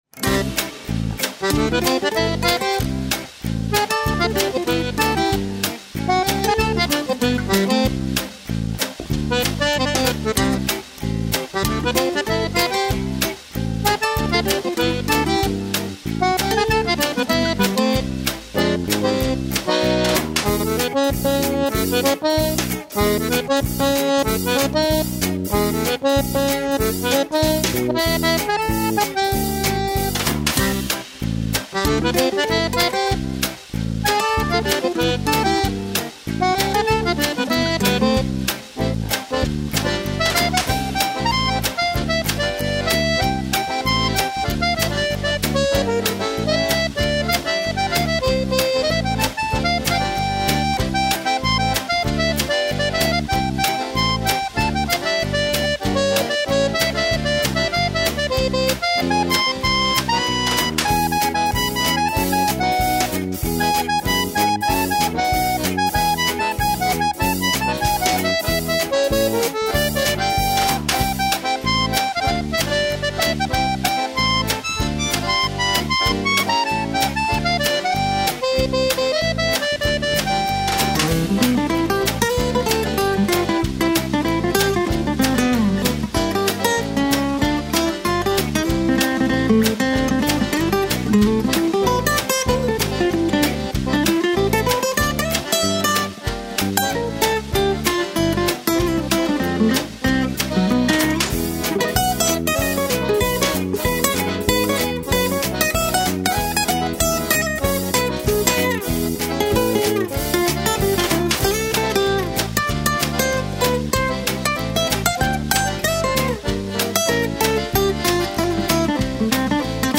Akkordeon
Gitarren